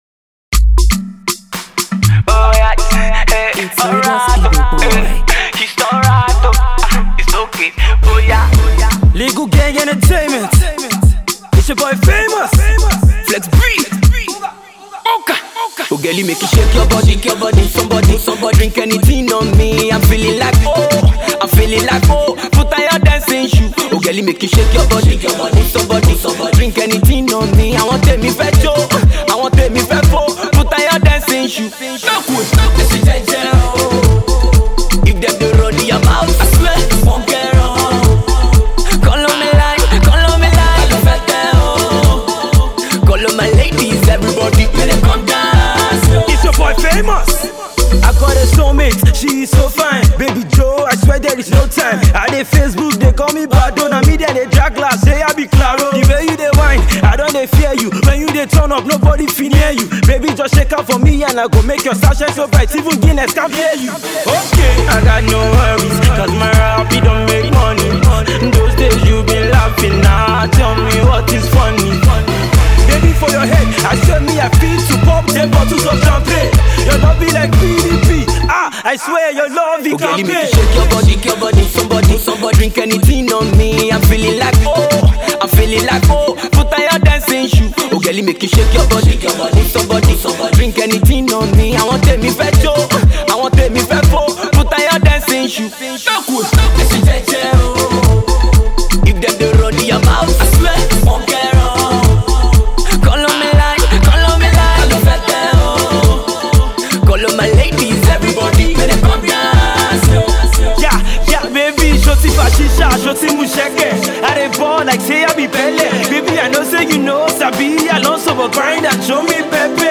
This is a certified club banger.